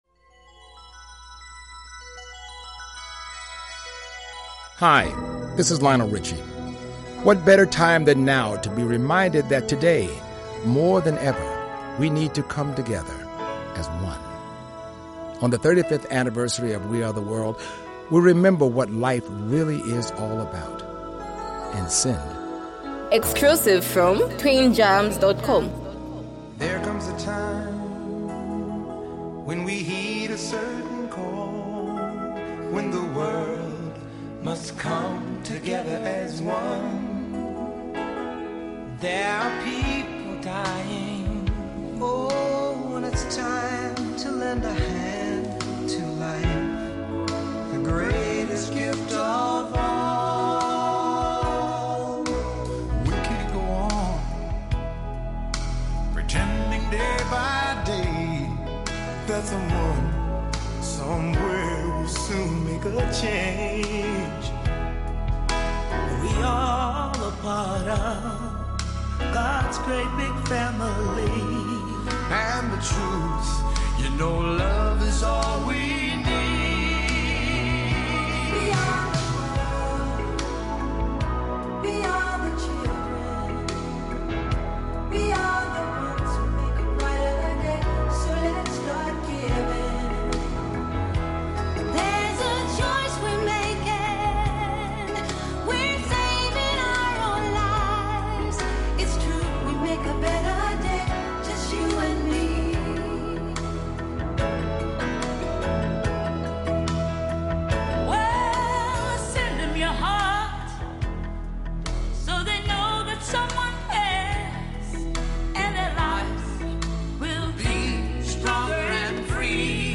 charity single